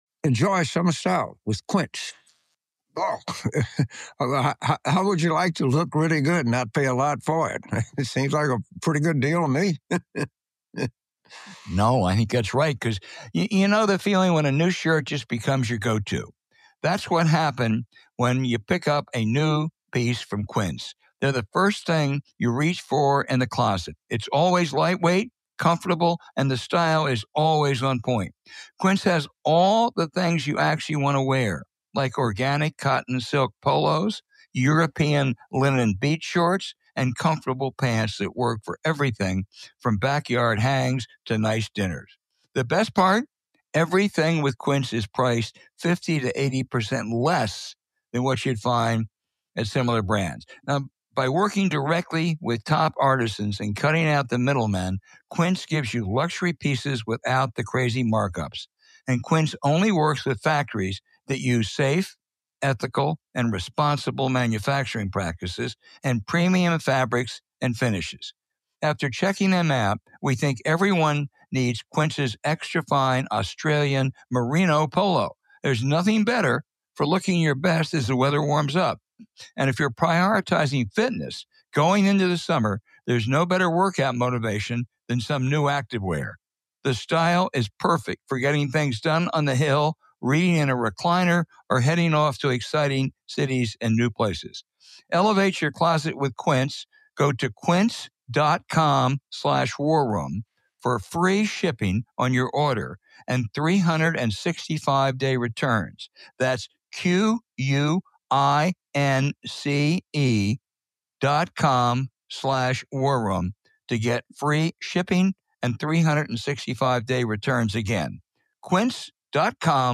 James and Al lament the Trumpist takeover of the House Oversight Committee and the expiry of childcare support, and talk with financial maven Roger Altman. They look at the improving strength of the economy, and weigh the options of Jay Powell as the risks of recession and inflation's dangers seem to be declining. Then, they break down the effects of China’s economic malaise and why they won’t surpass us, and explain why one of our biggest upcoming challenges will be spending the massive funds allocated to our country from the infrastructure bill and other initiatives.